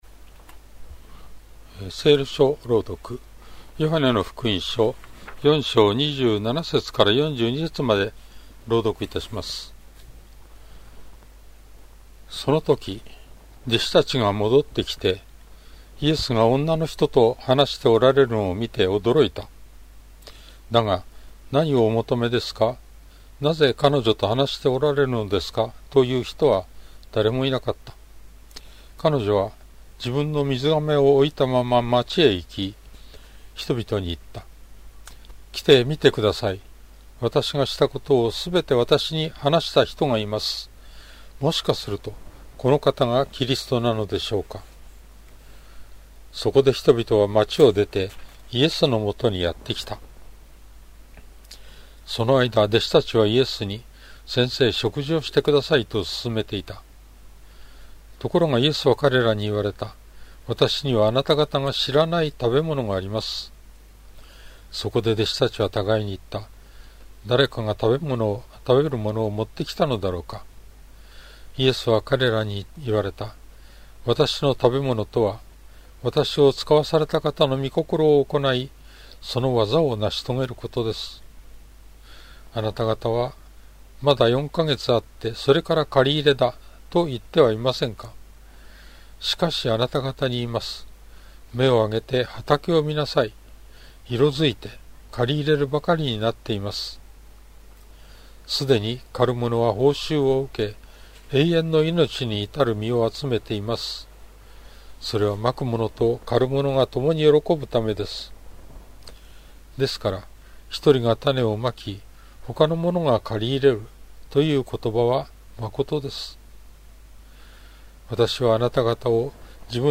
BibleReading_J4.27.mp3